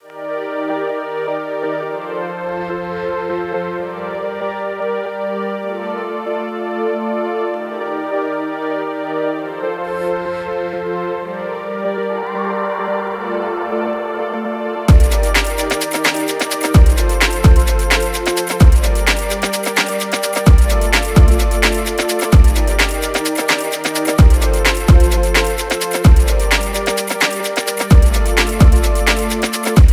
Genre: IDM/Experimental